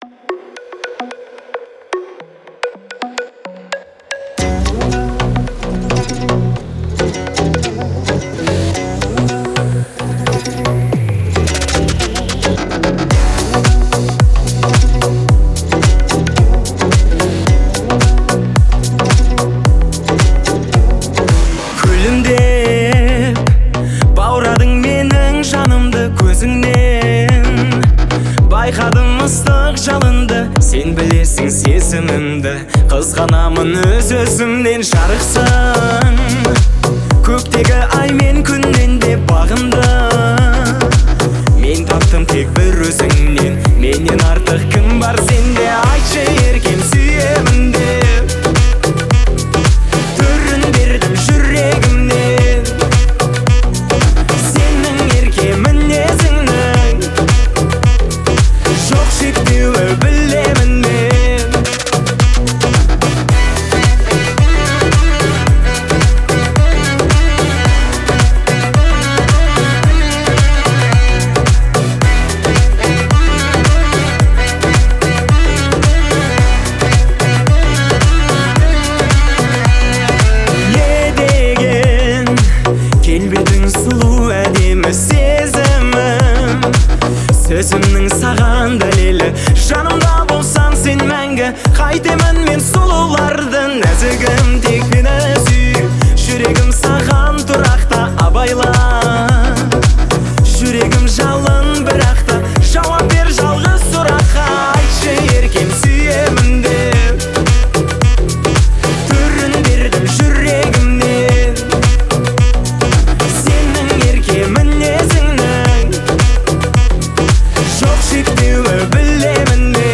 это романтическая песня в жанре казахской поп-музыки